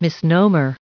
510_misnomer.ogg